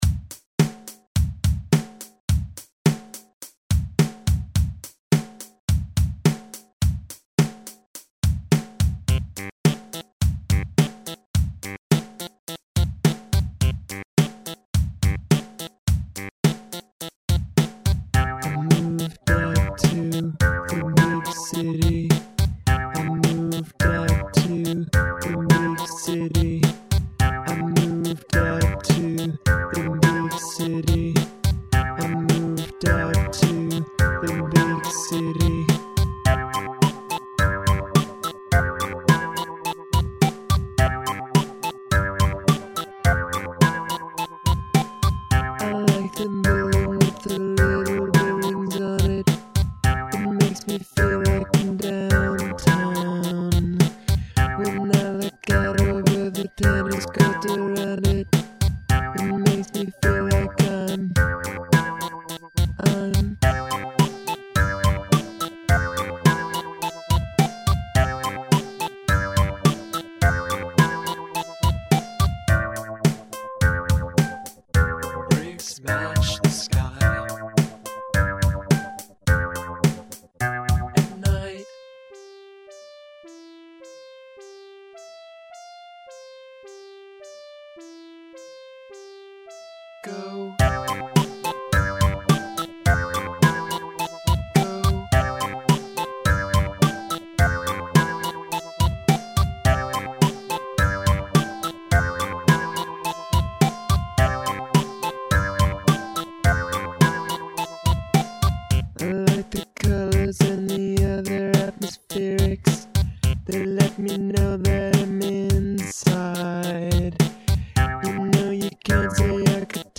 i'm posting this now because i never really put this song on anything but everyone who has heard it seems to like it a lot. i think that's partly because it's so different from anything else i've done, mainly in that i wasn't at all worried about what i was saying in the song, mostly just about how it sounded (my first fruityloops experiment--but the feedback is real!).
i don't know if it's that it's about buildings, but to me this is like a mix of the cure--the throatiness of your singing--and the talking heads, with the oddball sounds and the exuberant synth lines. i love the rhythm of the lyrics, and even though you say you weren't worried about them, they're lovely. i especially like the second set of verses, with the line about "architecture... too many syllables," and then the dialog between you and she, which seems to make this song really be about more than the building. deep. cute. amazing.
6. the music is great. the warbly and staccato bits fit well together.
My_Pal_Max_(demo).mp3